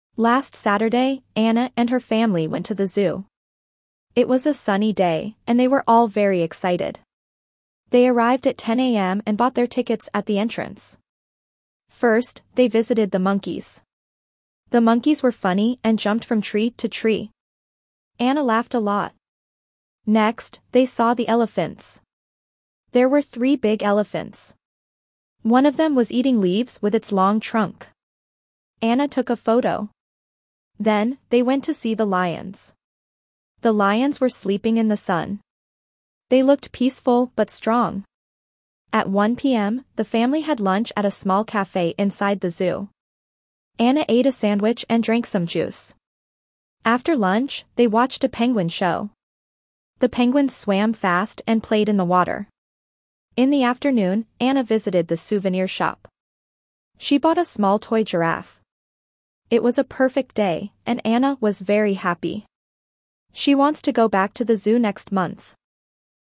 Reading A2 - A Day at the Zoo
3.-A2-Reading-A-day-at-the-zoo.mp3